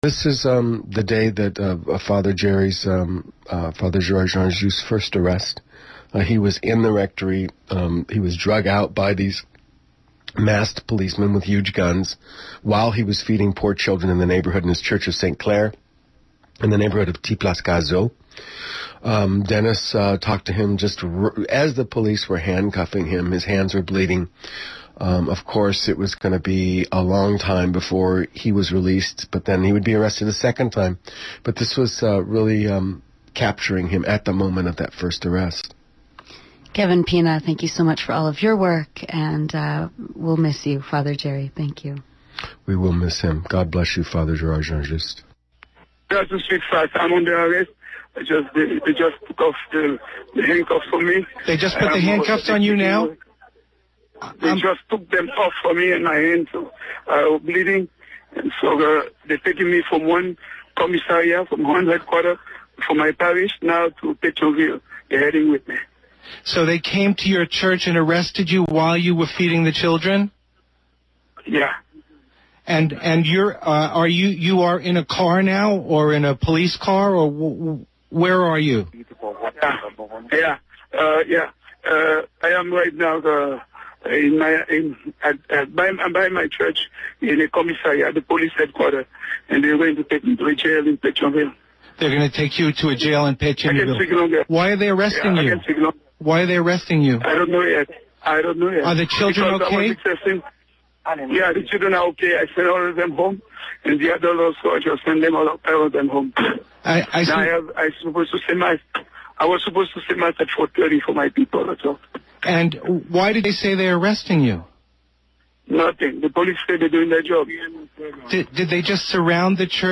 Audio Recording from Flashpoint
interviewing a wounded and handcuffed Father Jean Juste in the process of his first arrest after 2004 Bush Regime change in Haiti on Oct 13 2004